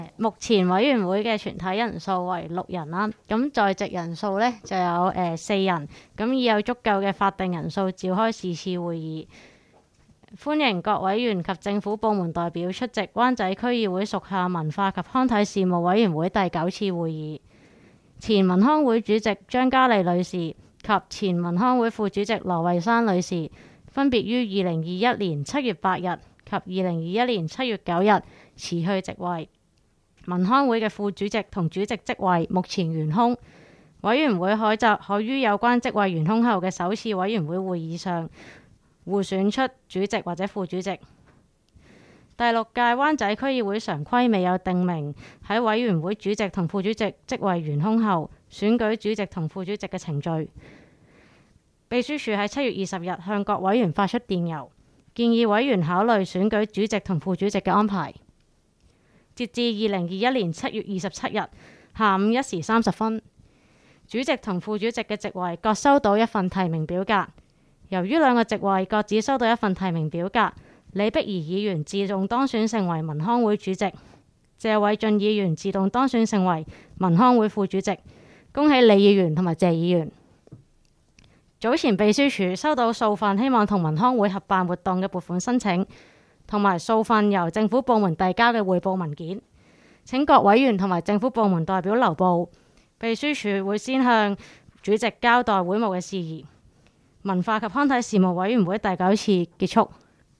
委员会会议的录音记录
文化及康体事务委员会第九次会议 日期: 2021-07-27 (星期二) 时间: 下午2时30分 地点: 香港轩尼诗道130号修顿中心21楼 湾仔民政事务处区议会会议室 议程 讨论时间 1 选举文化及康体事务委员会主席/副主席 00:01:40 全部展开 全部收回 议程:1 选举文化及康体事务委员会主席/副主席 讨论时间: 00:01:40 前一页 返回页首 如欲参阅以上文件所载档案较大的附件或受版权保护的附件，请向 区议会秘书处 或有关版权持有人（按情况）查询。